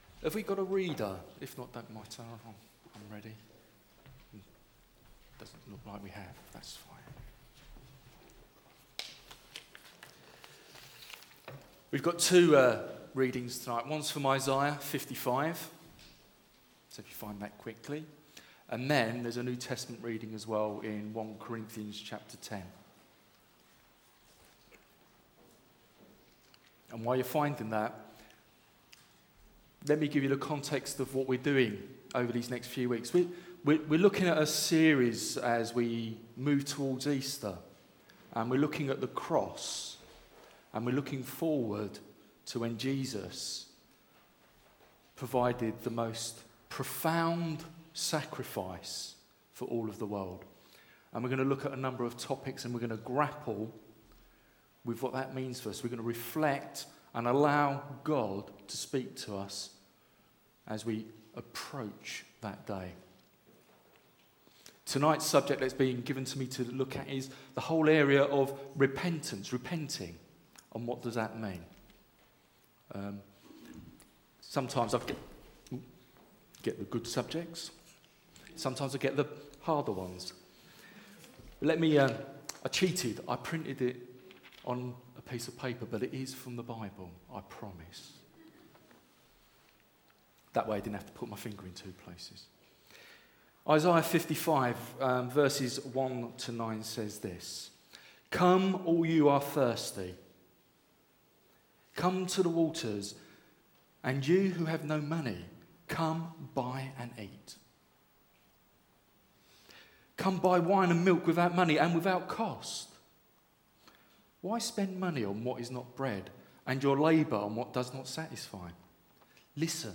A message from the series "LENT."